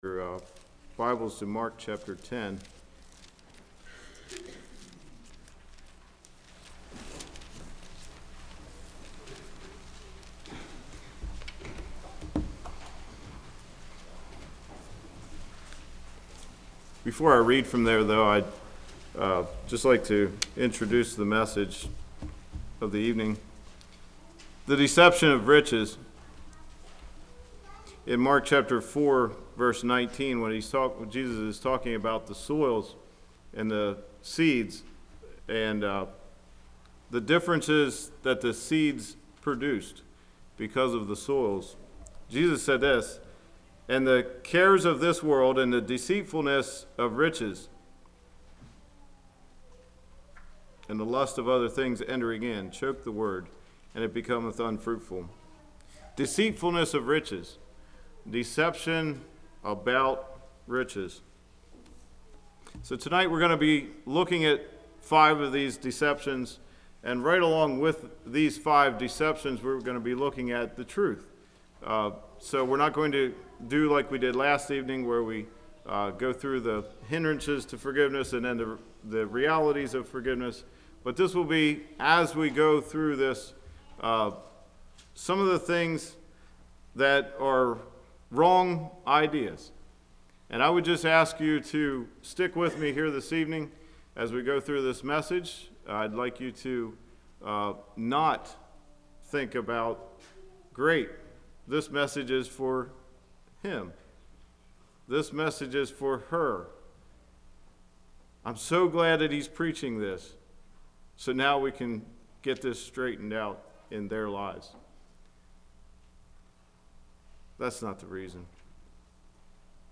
Congregation: Blue Ridge